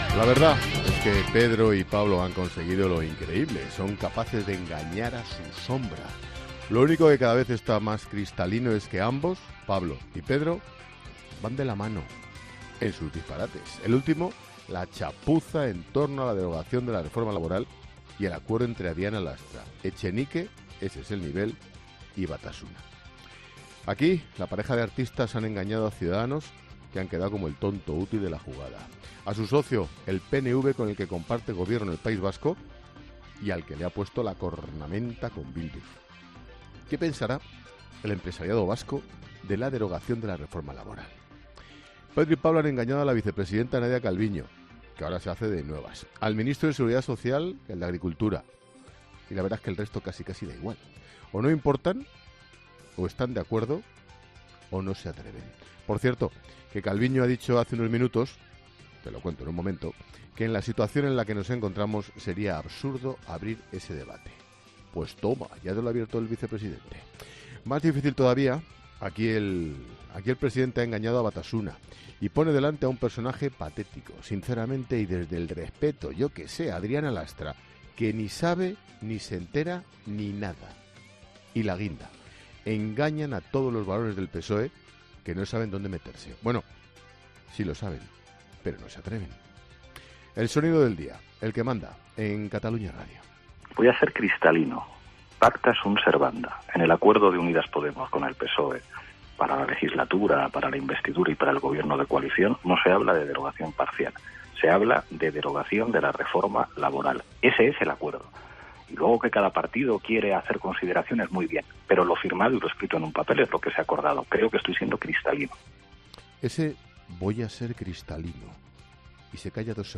El presentador de 'La Linterna' ha reflexionado sobre el polémico acuerdo entre el Gobierno y Bildu nada más arrancar 'La Linterna' de este jueves